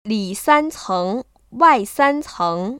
[lĭ sāncéng wài sāncéng] 리싼청와이싼청  ▶